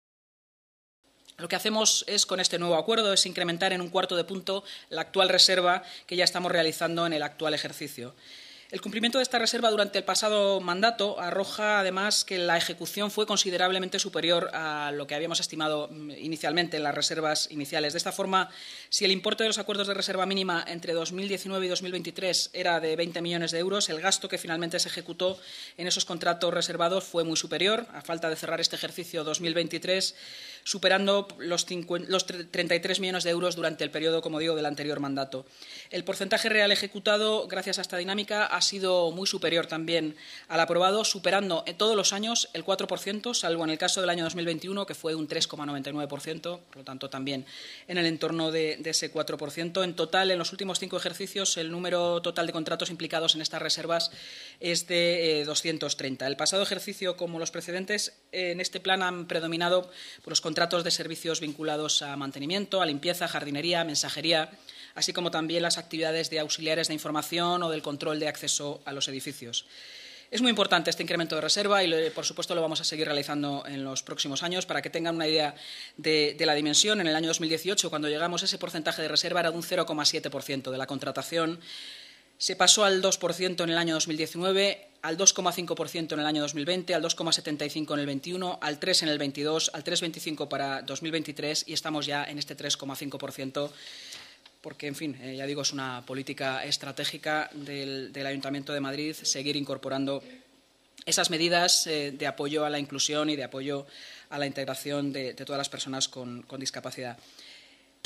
El Ayuntamiento de Madrid destinará 5,8 millones de euros durante el año 2024, un 3,5 % del total de la contratación municipal, a contratos socialmente responsables, es decir, aquellos reservados para organismos que tienen por objeto la integración de personas con discapacidad o en situaciones de exclusión social. La Junta de Gobierno, a propuesta del Área de Economía, Innovación y Hacienda, ha aprobado esta mañana esta iniciativa que afecta al Ayuntamiento de Madrid, los organismos autónomos y el sector público municipal y que incrementa en un cuarto de punto la reserva que se está realizando en el actual ejercicio, según ha explicado la vicealcaldesa y portavoz municipal, Inma Sanz, en rueda de prensa.